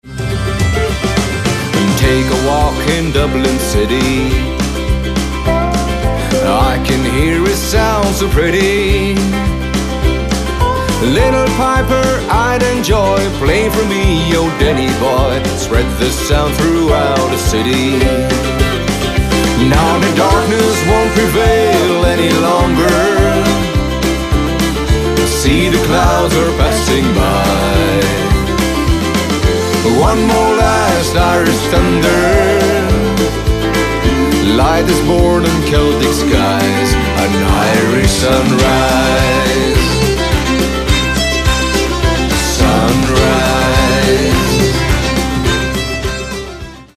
Stil / Art: Country, Bluegrass, Country-Rock
Aufgenommen: Hilltop Studios Nashville, U.S.A.